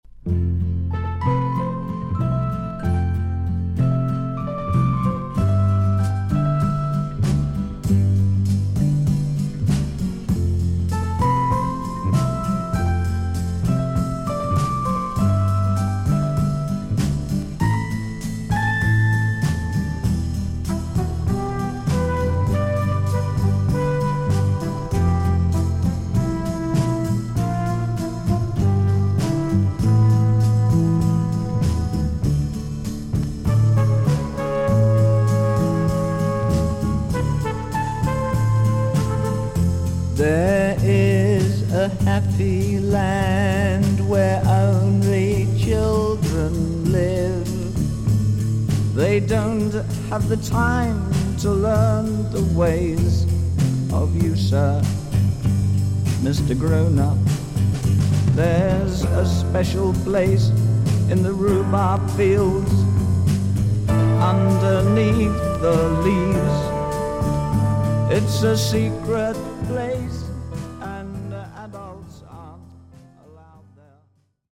ステレオ化した再編集盤。
VG++〜VG+ 少々軽いパチノイズの箇所あり。クリアな音です。